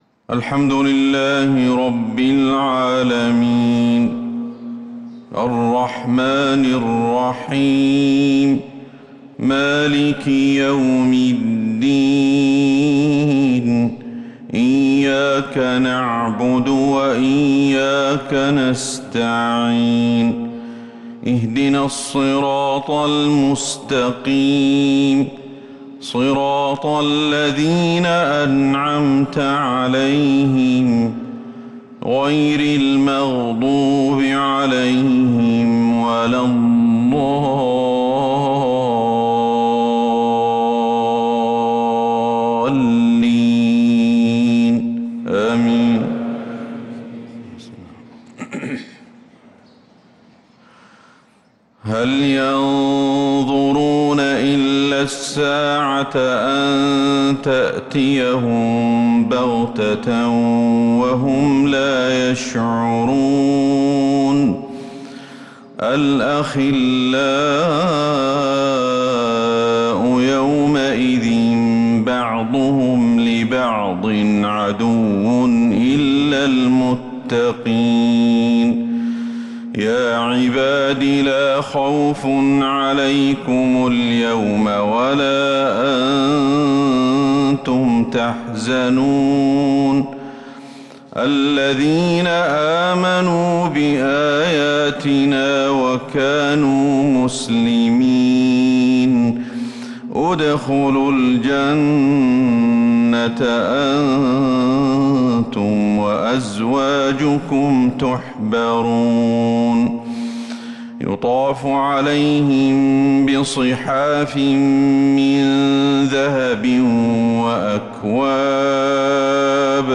فجر السبت 4-7-1446هـ خواتيم سورة الزخرف66-89 | Fajr prayer from Surat Az-Zukhruf 4-1-2025 > 1446 🕌 > الفروض - تلاوات الحرمين